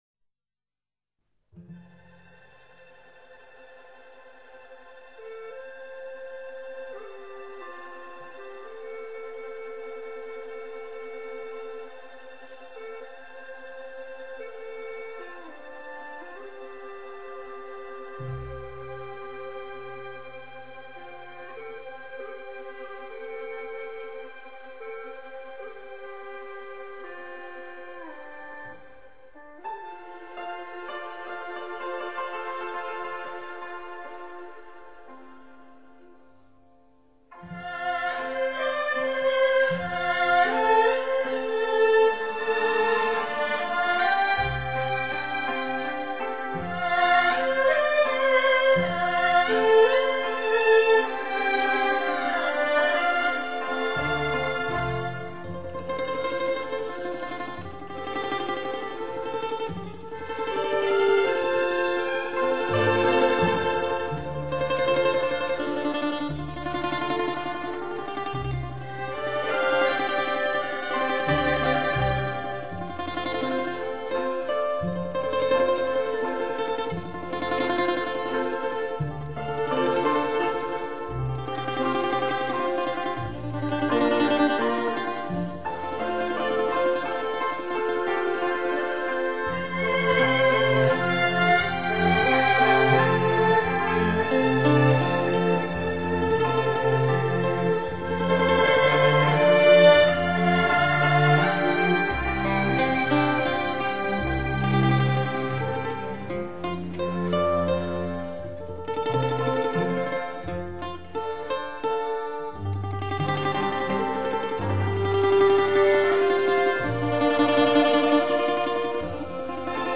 这是一首根据傣族民歌音调创作而成的中阮独奏曲，
全曲优美、抒情，演奏中运用中阮的滑音、吟音、揉弦等手法，